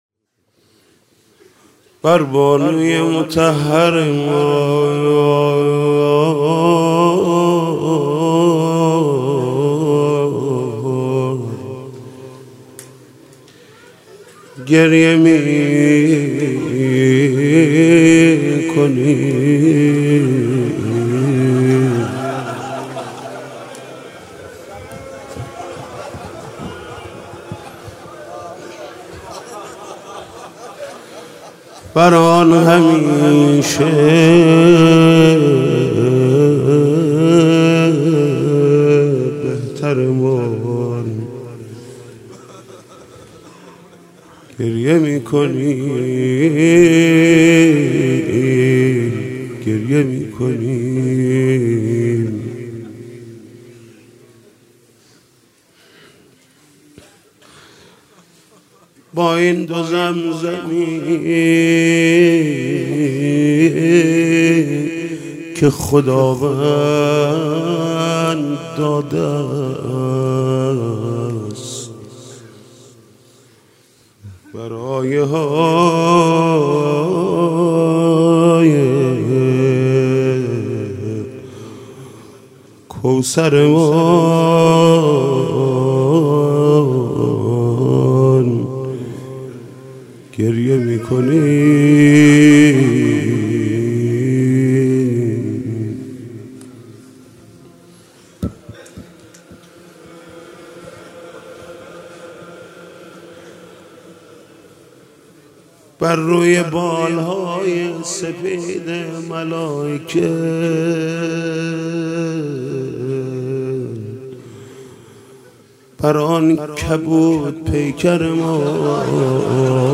مداحی و نوحه
[مناجات]